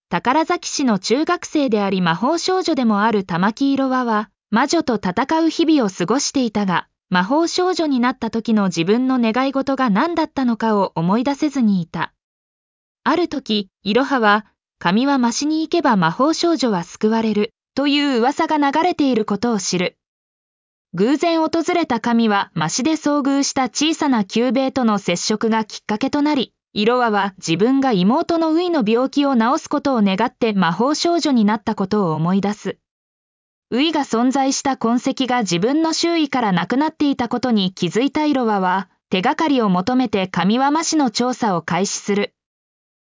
ナレーション 音読さん